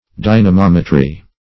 Search Result for " dynamometry" : The Collaborative International Dictionary of English v.0.48: Dynamometry \Dy`na*mom"e*try\, n. The art or process of measuring forces doing work.